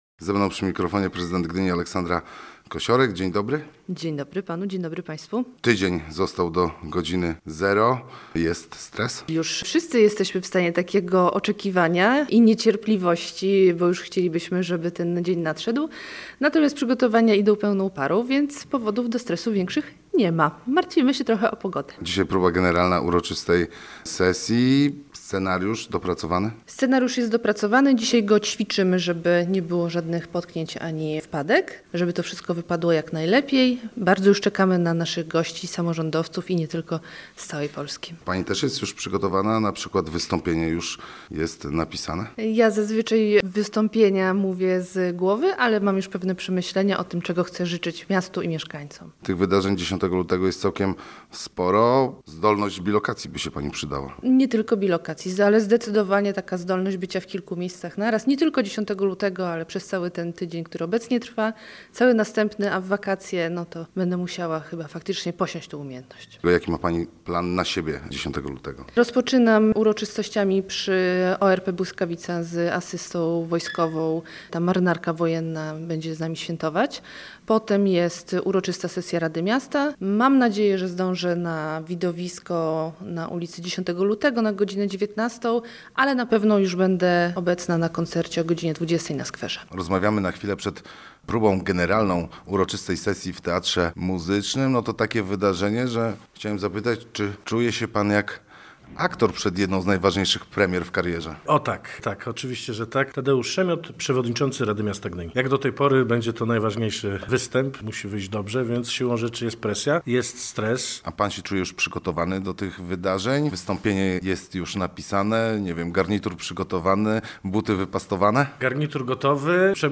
Aleksandra Kosiorek, prezydent Gdyni, zdradza, że jedynym elementem wywołującym stres, są prognozy pogody.
Posłuchaj materiału naszego reportera